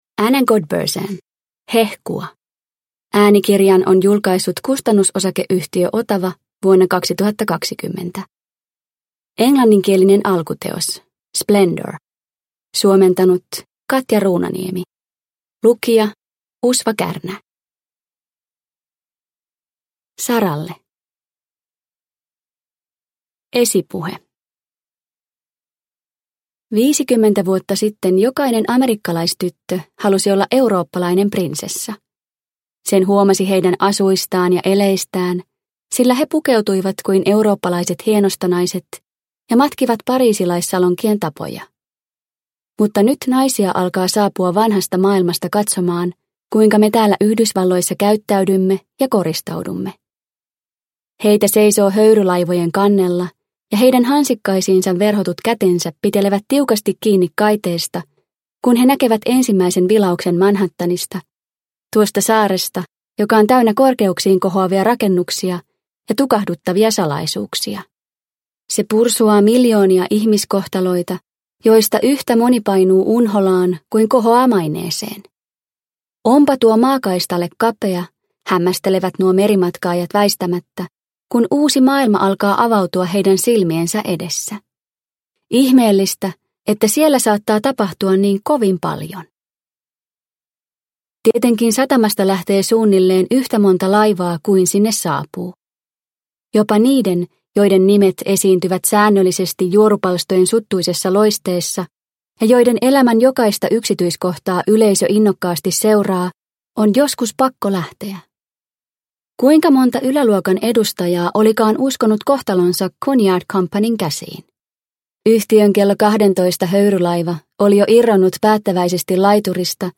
Hehkua – Ljudbok – Laddas ner